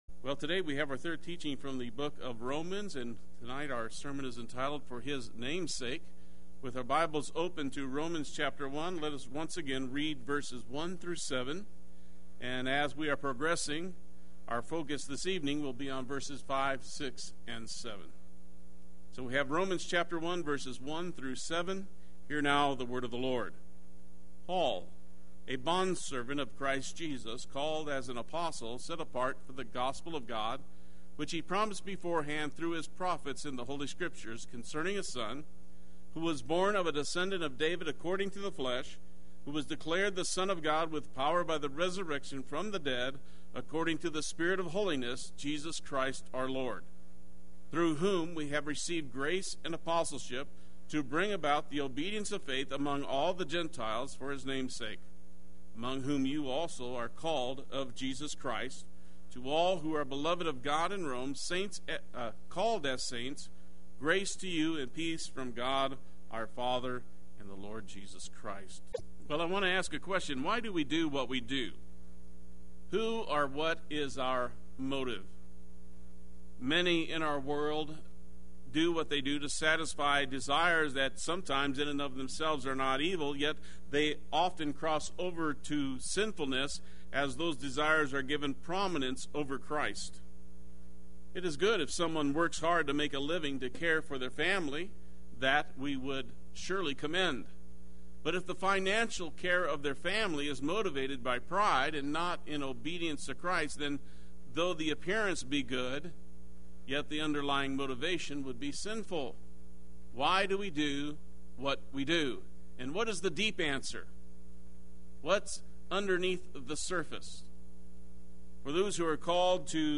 Play Sermon Get HCF Teaching Automatically.
For His Name’s Sake Wednesday Worship